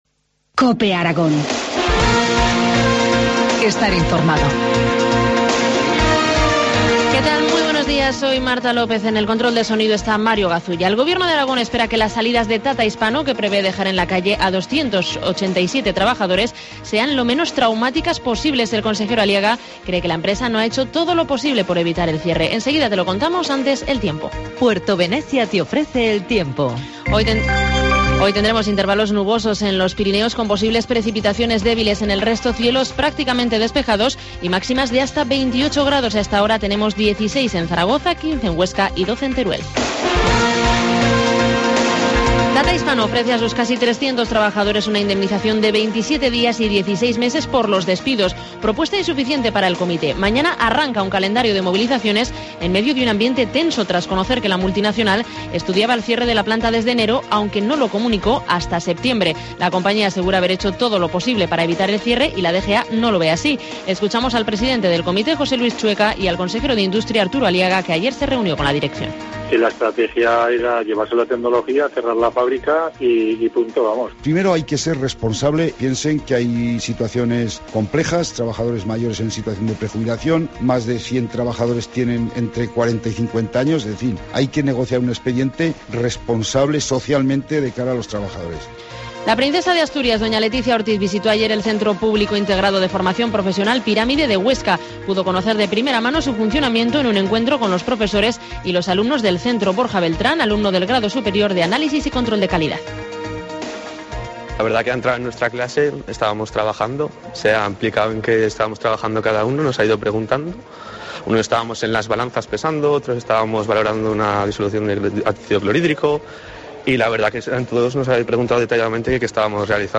Informativo matinal, miércoles 16 de octubre, 8.25 horas